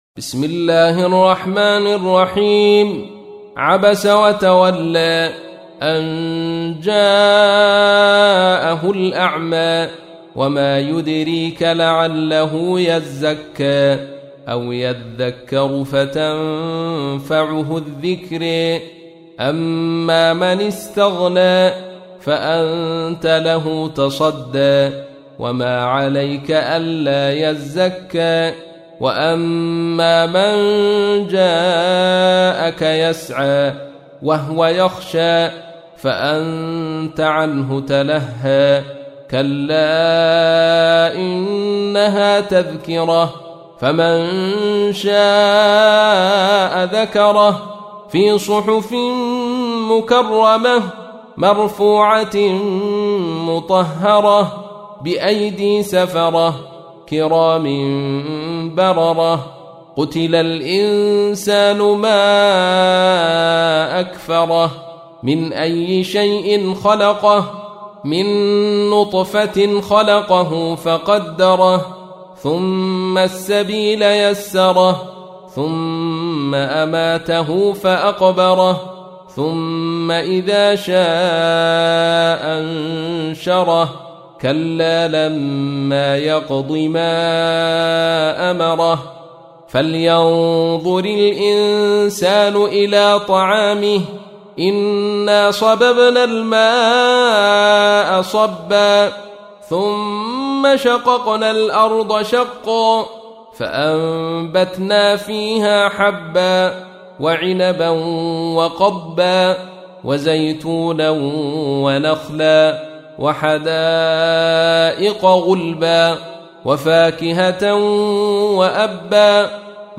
تحميل : 80. سورة عبس / القارئ عبد الرشيد صوفي / القرآن الكريم / موقع يا حسين